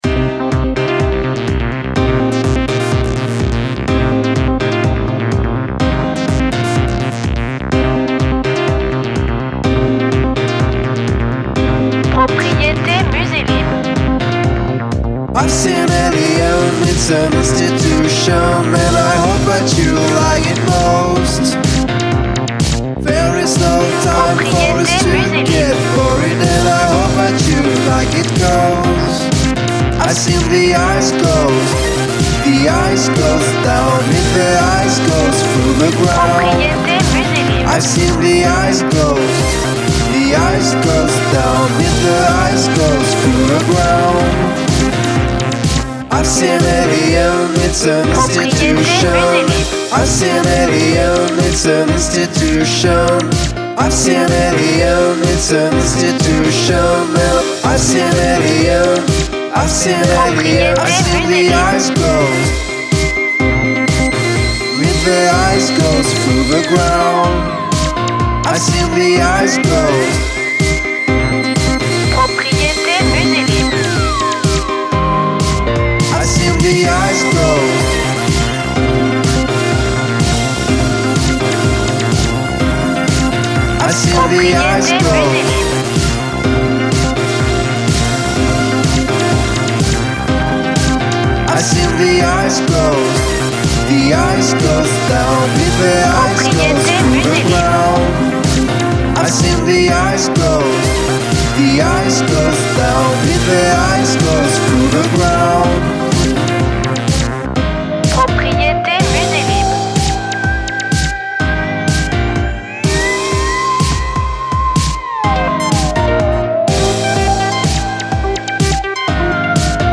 BPM Fast